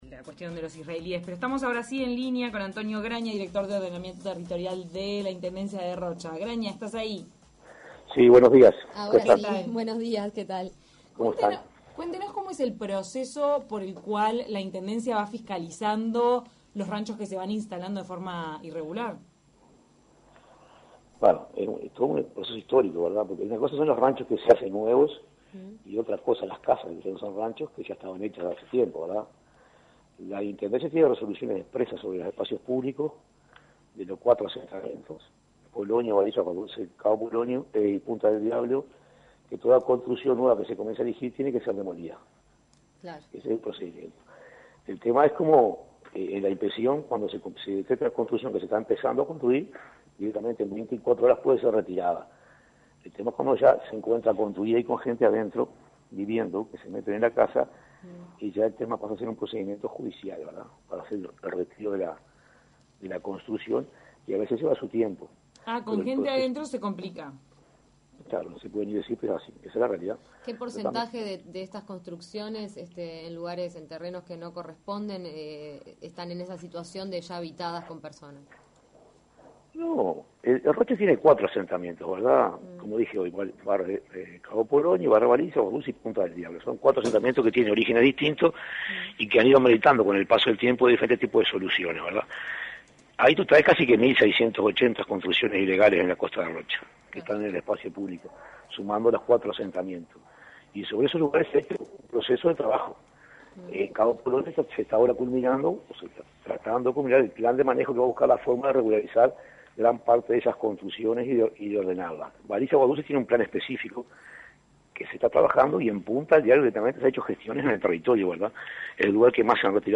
De taquito a la mañana se contactó con Antonio Graña, director de Ordenamiento Territorial de la Intendencia de Rocha.